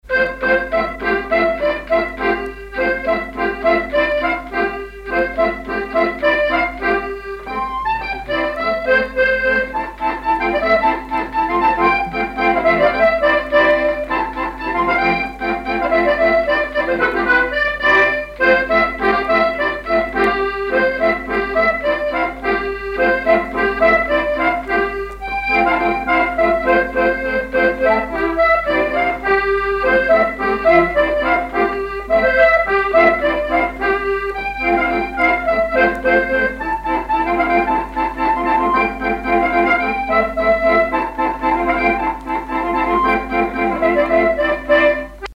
danse : orsay
accordéon diatonique
Pièce musicale inédite